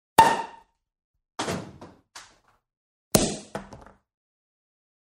Звуки пробки бутылки
Звук упавшей пробки на пол